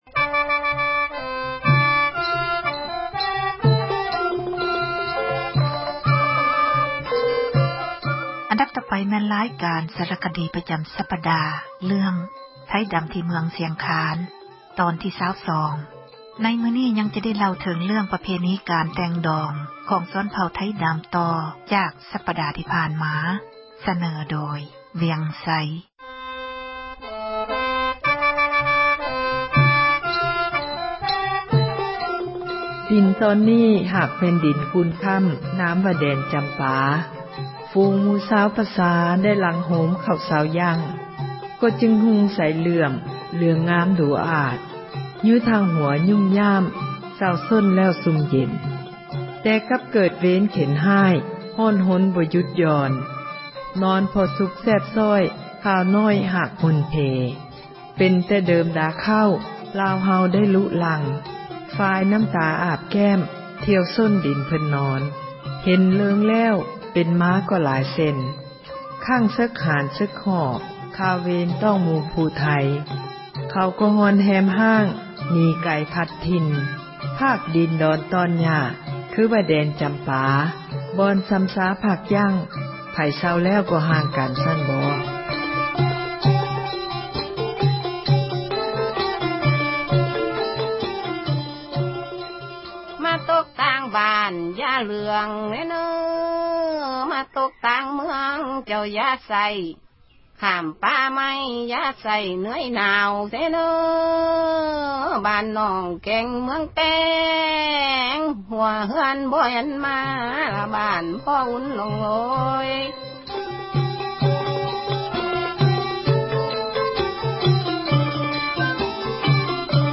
ຣາຍການ ສາຣະຄະດີ ປະຈຳ ສັປດາ ເຣື້ອງ ”ໄທດຳ ທີ່ເມືອງ ຊຽງຄານ” ຕອນທີ 22 ໃນມື້ນີ້ ຍັງຈະໄດ້ ເລົ່າເຖິງເຣື້ອງ ປະເພນີ ການແຕ່ງດອງ ຂອງ ເຜົ່າໄທດຳ.